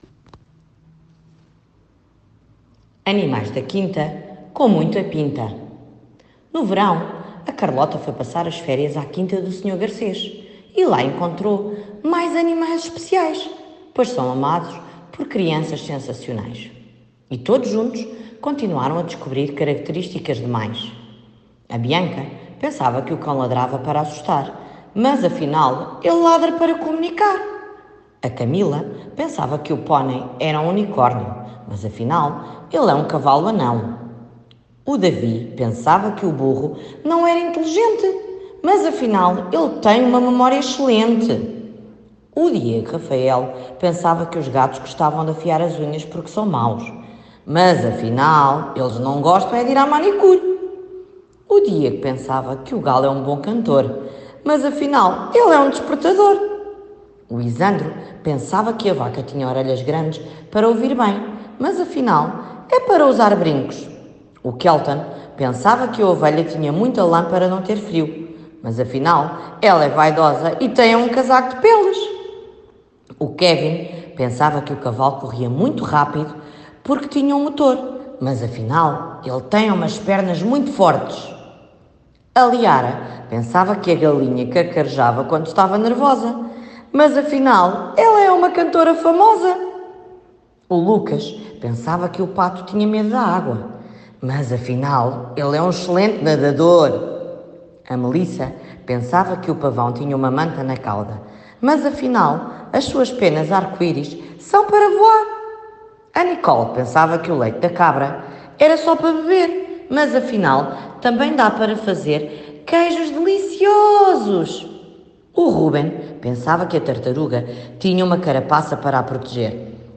Áudio do livro